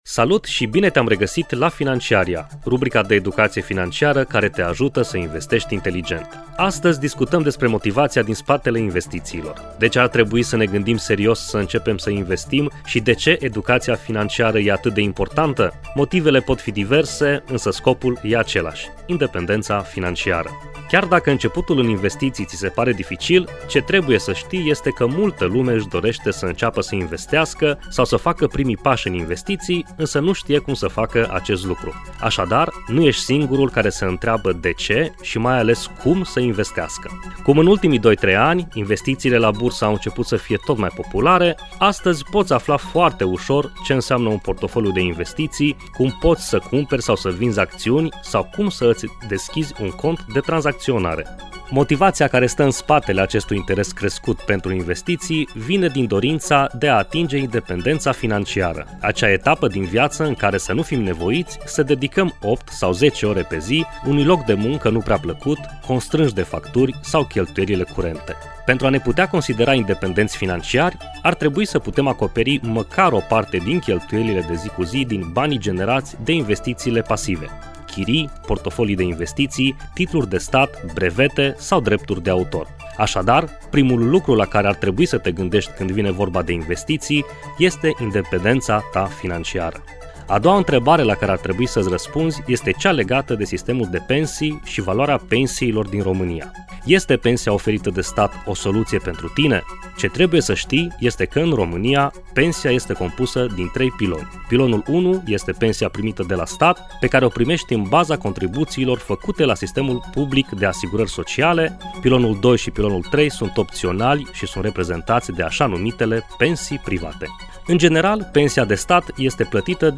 expert în investiții